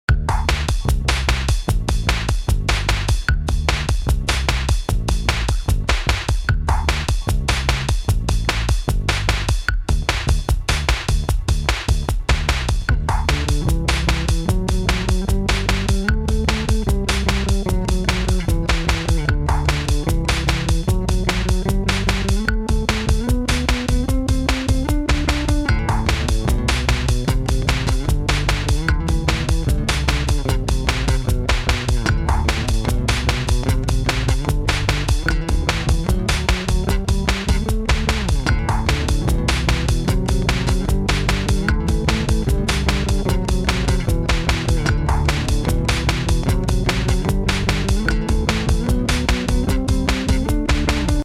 home of the daily improvised booty and machines -
3 bass grooves